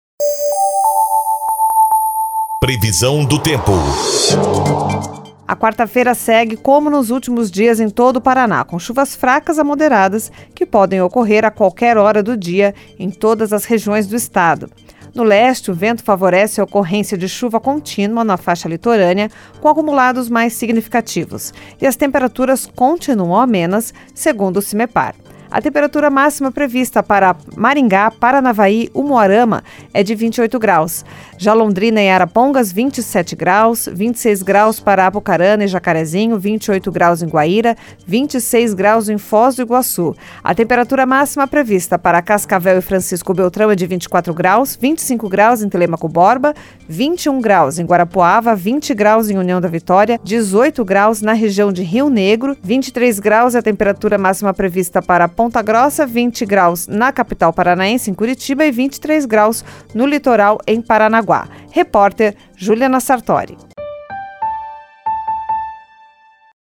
Previsão do Tempo 24/01